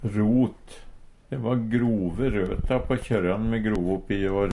rot - Numedalsmål (en-US)